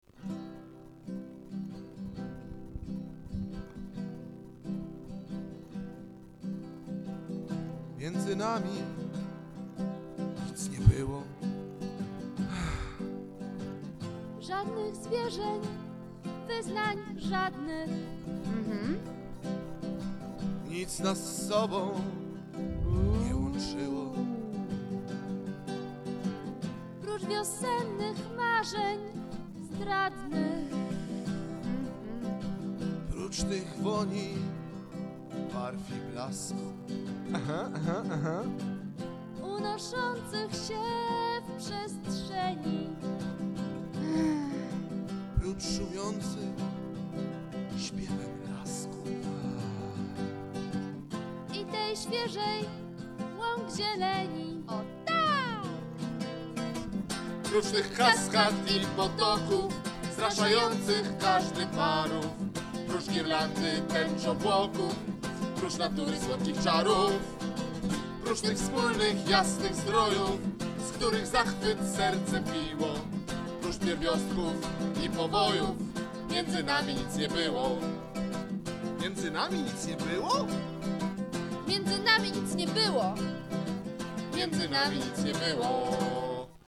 Nagrania dokonaliśmy w sierpniu 2004 bardzo domowym sposobem. Efektem jest 20 plików mp3 nieco szumiacych i dosyć cichych, ale za to naszych własnych.